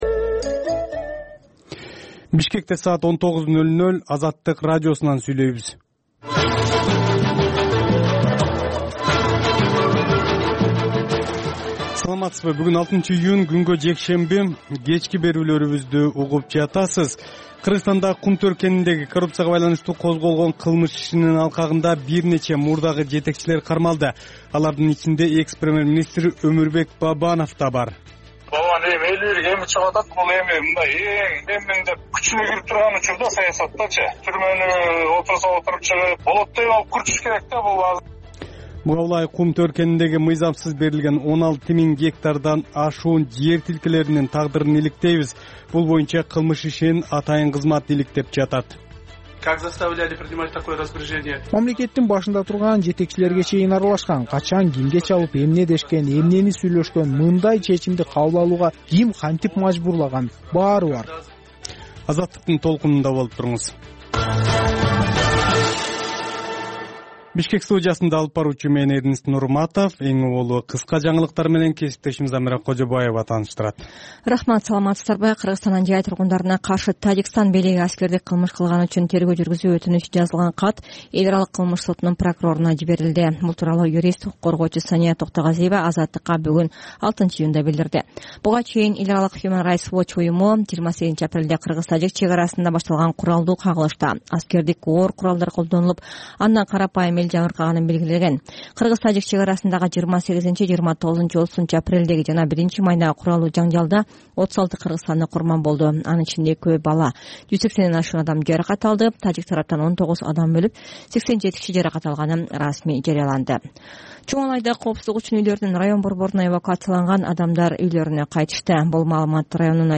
Бул үналгы берүү ар күнү Бишкек убакыты боюнча саат 19:00дөн 20:00гө чейин обого түз чыгат.